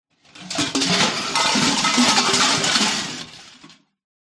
Звуки кастрюли, сковороды
Металлические кастрюли и сковородки много падение удар краш